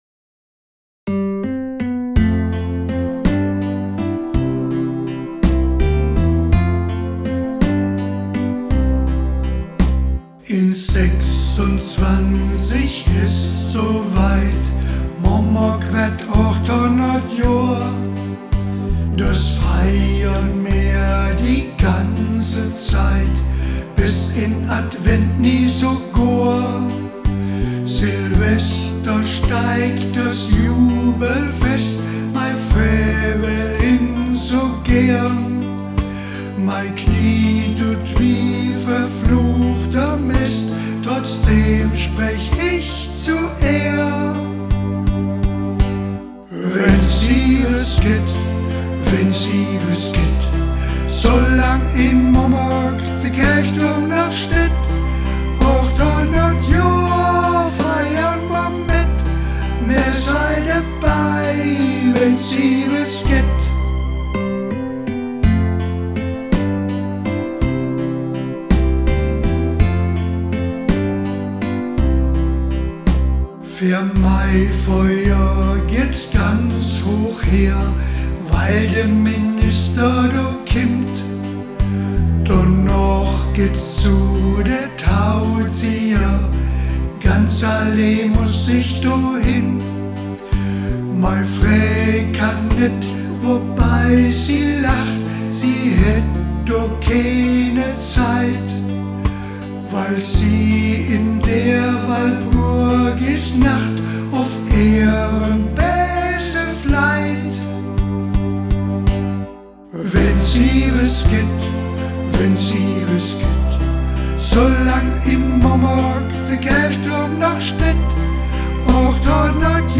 Silvesterfeier 2025-2026 im DGH Momberg,
wurde im Laufe des Abend vorgestellt und von dem gesamten Publikum mitgesungen. Der eingängige Ohrwurm avancierte sofort zur Hymne des Festjahres.